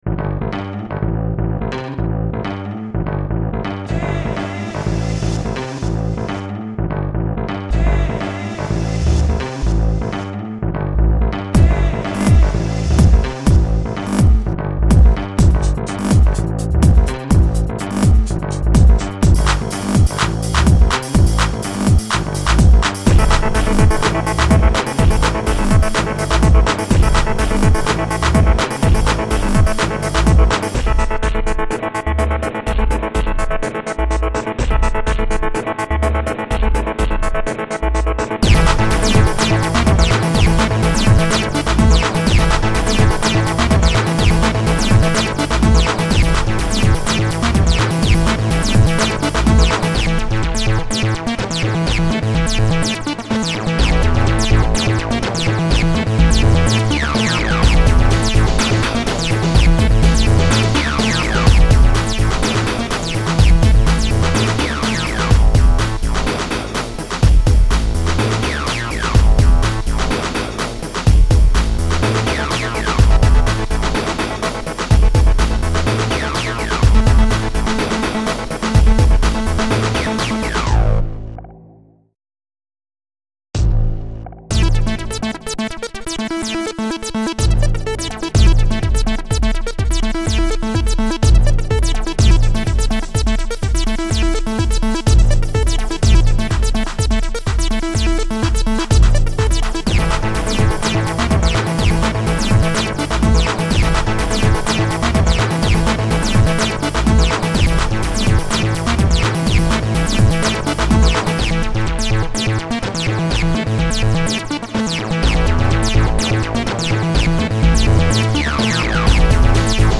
[ Original Format : Impulse Tracker